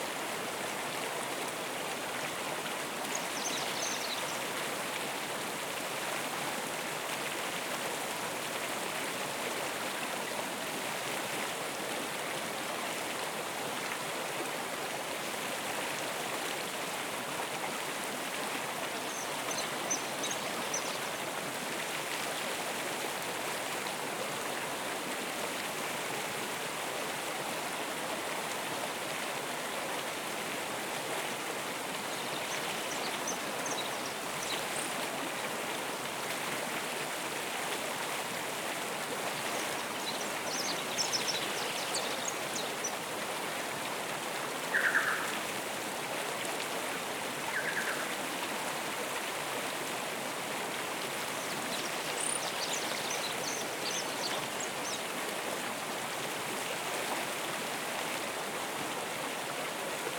AmbientRiver.wav